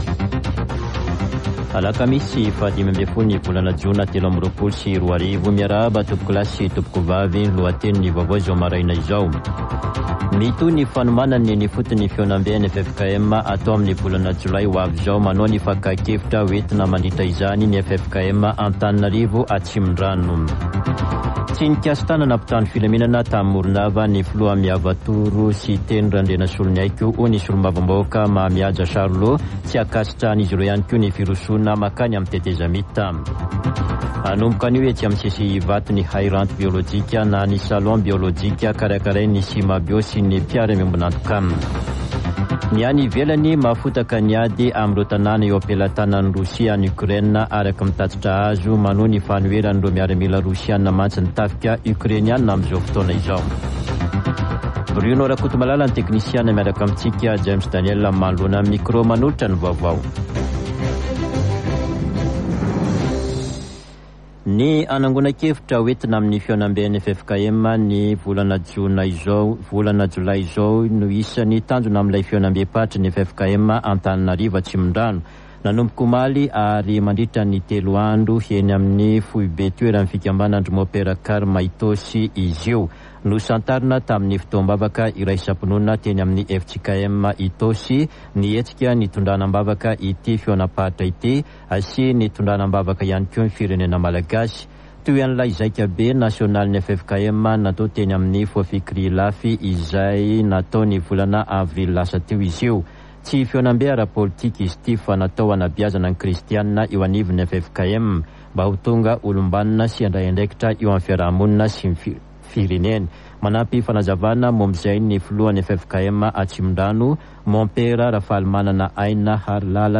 [Vaovao maraina] Alakamisy 15 jona 2023